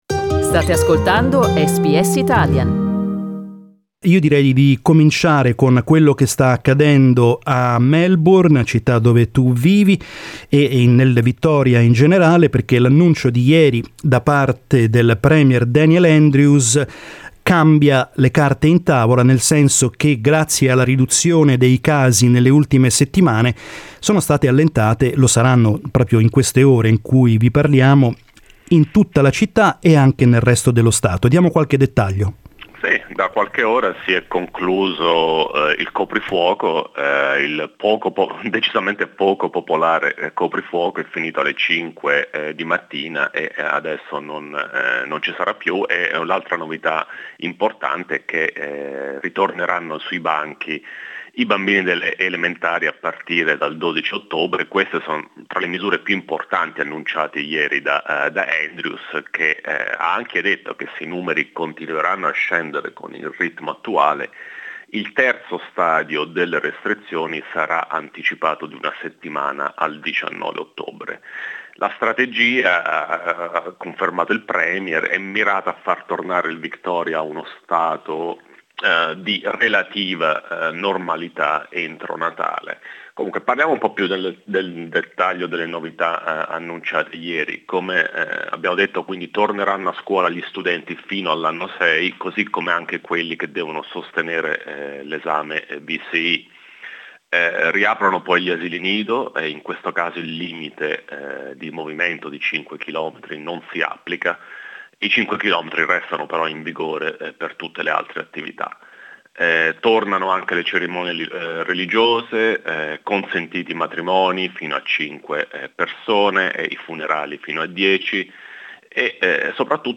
Meanwhile, the situation continues to improve in the other States as well, while, at the federal level, a deficit explosion is worrying. We reviewed the lockdown news in Victoria with our Australian politics correspondent